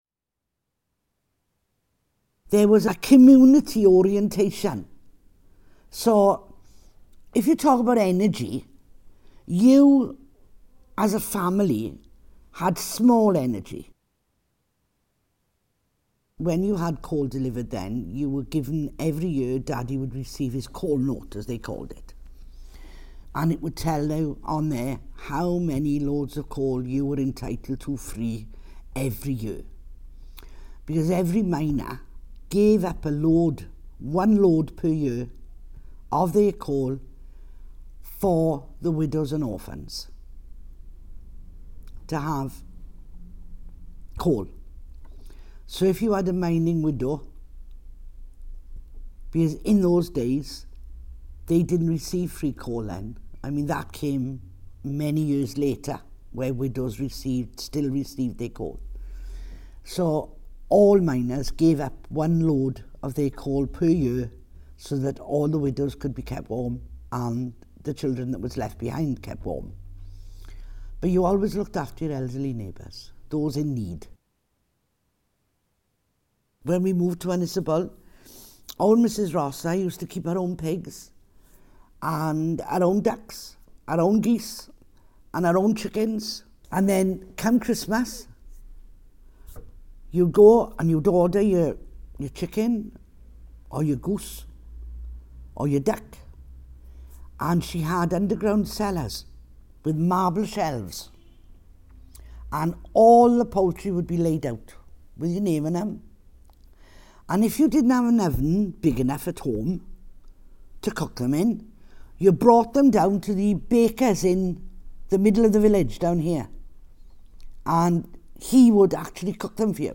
Edited oral history interview Ynysybwl 2015